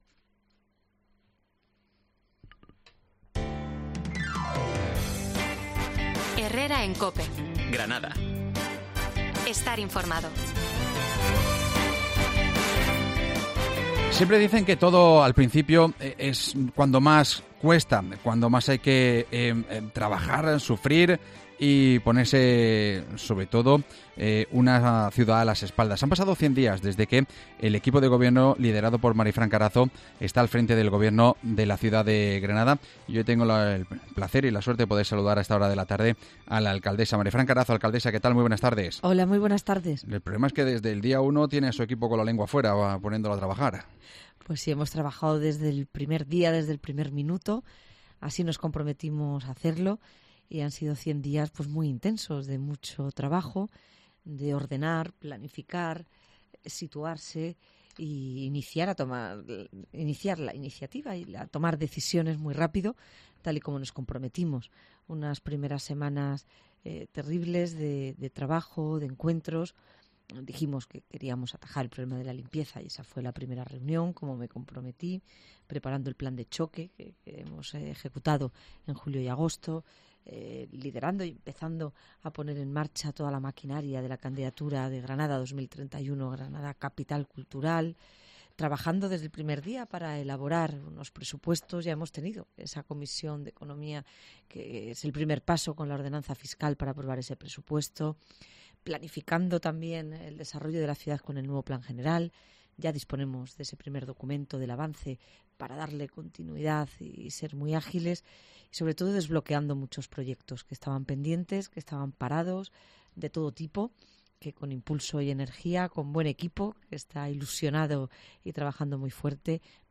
AUDIO: La alcaldesa de Granada cumple 100 días en el cargo y ha repasado los principales proyectos de futuro y presente para la ciudad.